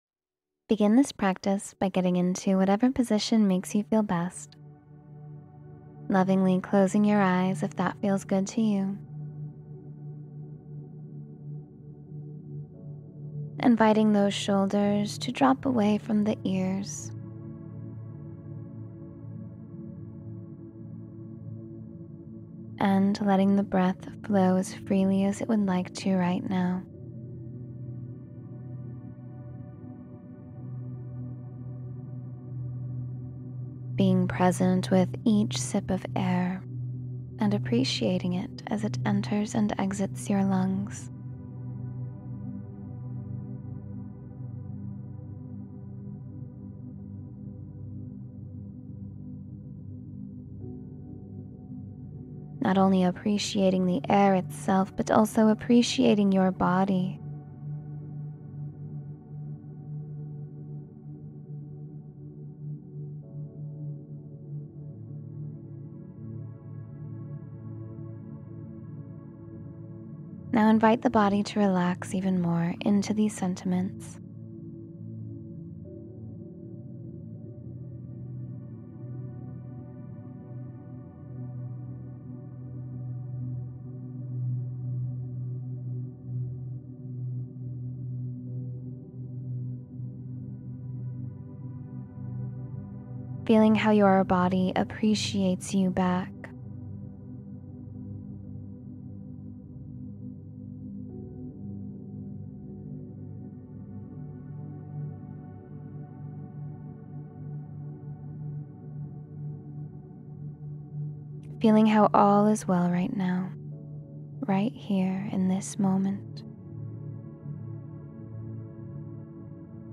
Take Charge of Your Own Happiness — Meditation for Self-Confidence and Joy